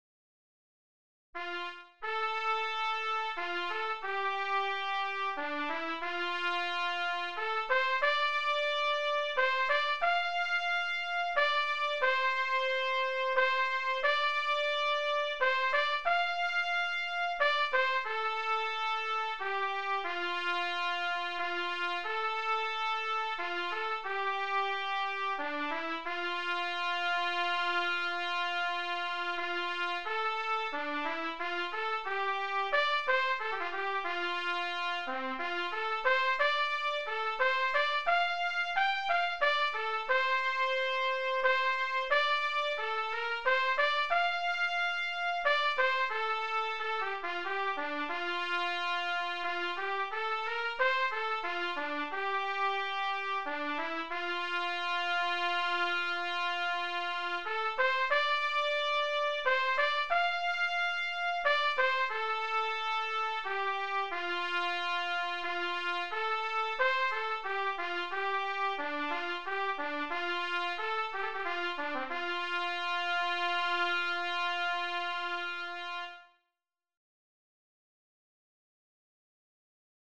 DIGITAL SHEET MUSIC - TRUMPET SOLO